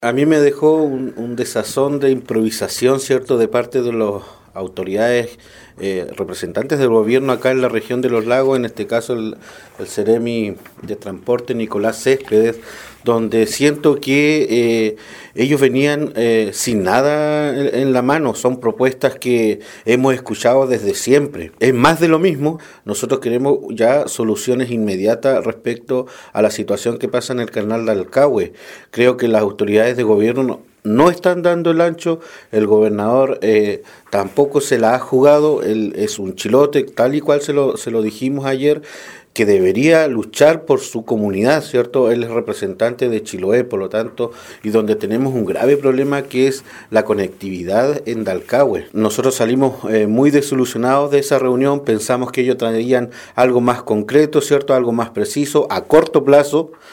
13-CONCEJAL-OSCAR-GALLARDO.mp3